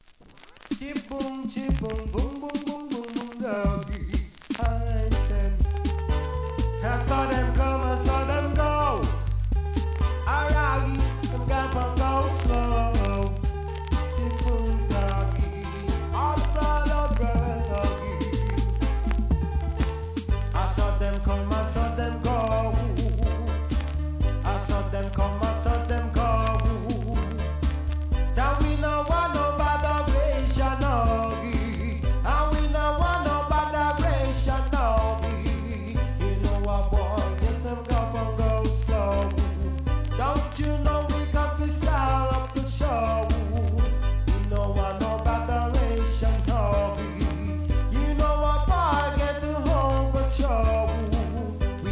REGGAE
流れるようなRHYTHM & VOCALのSING JAY STYLE!!